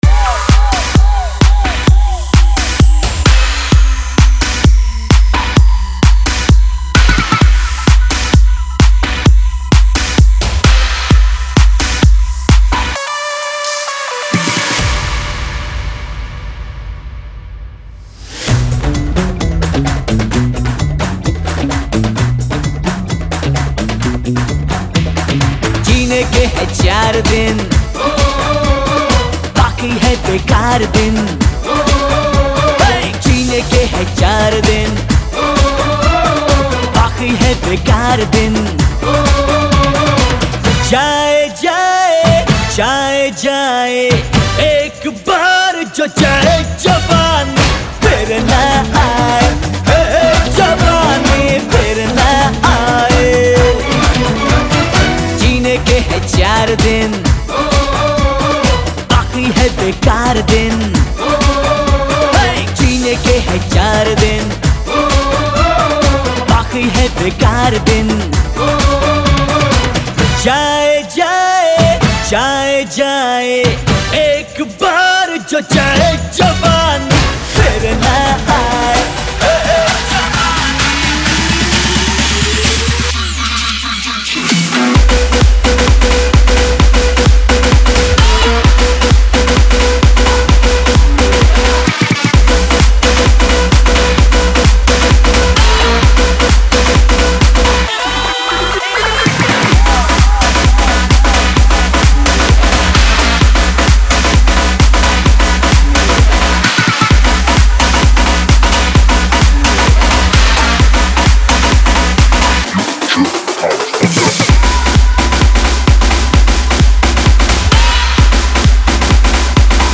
EDM Remix | Dance Remix Song
Dholki Remix Mp3 Song Free
Category: Latest Dj Remix Song